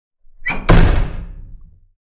Door Slam With Creak Sound Effect
Hear a short creak followed by a loud door shutting with force.
Genres: Sound Effects
Door-slam-with-creak-sound-effect.mp3